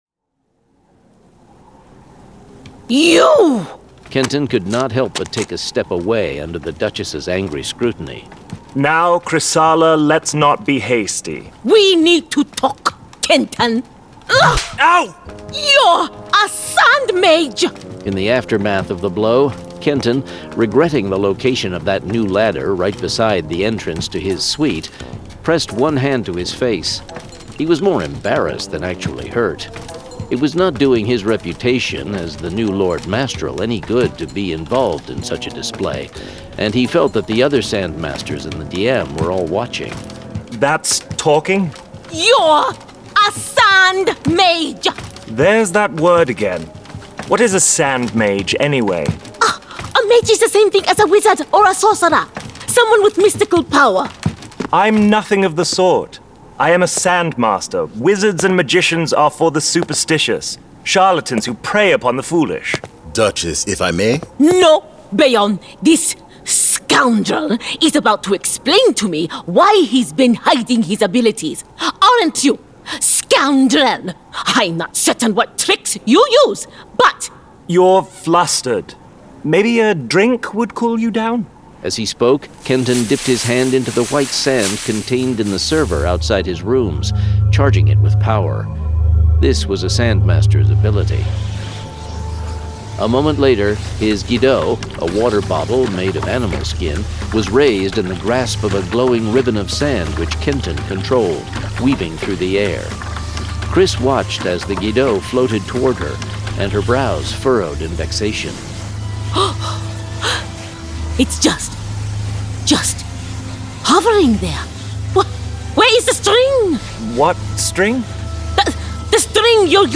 Full Cast. Cinematic Music. Sound Effects.
[Dramatized Adaptation]
For the first time ever in audio! GraphicAudio and Brandon Sanderson are proud to present WHITE SAND produced with a full cast of actors, immersive sound effects and cinematic music.